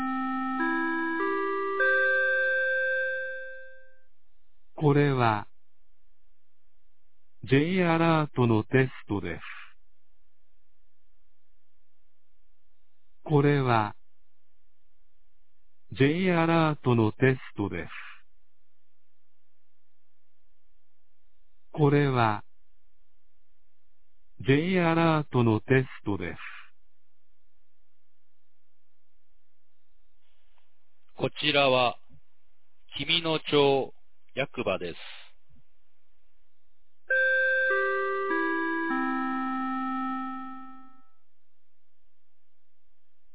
2023年09月20日 11時01分に、紀美野町より全地区へ放送がありました。
放送音声